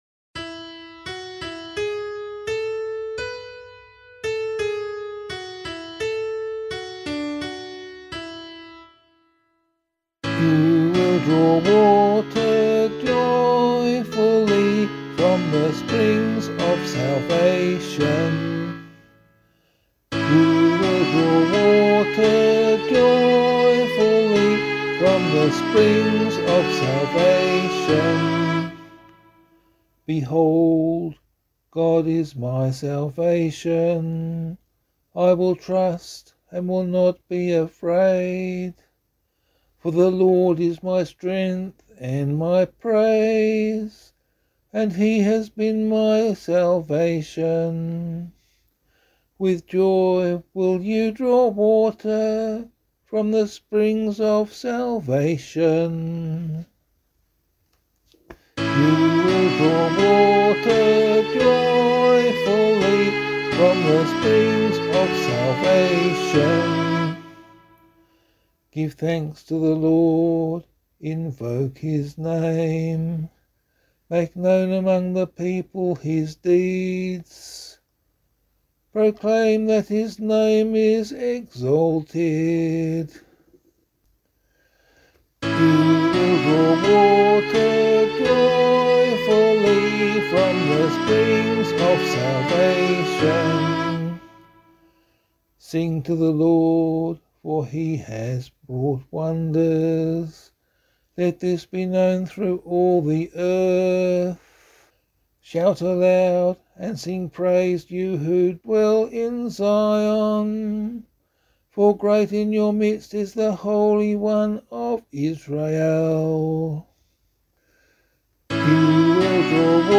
022 Easter Vigil Psalm 5 [Abbey - LiturgyShare + Meinrad 8] - vocal.mp3